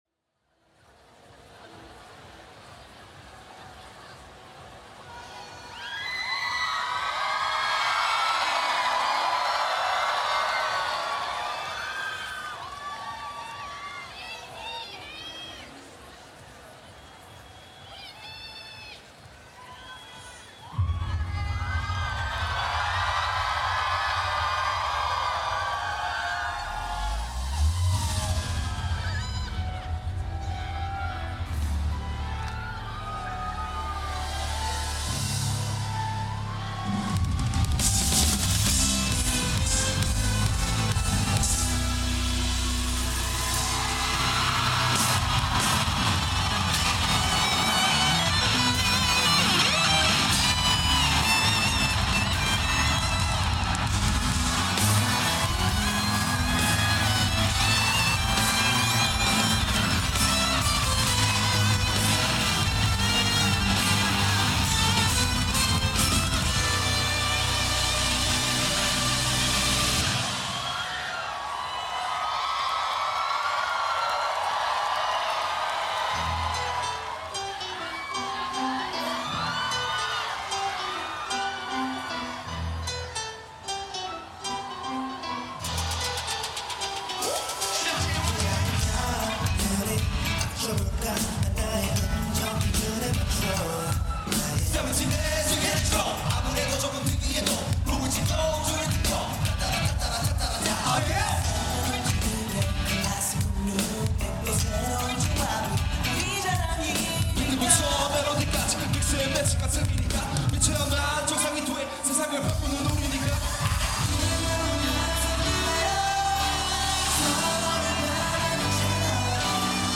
Kpop has its roots in 80s and 90s R&B – the brilliant bit
Kpop comes to Glasto 2024.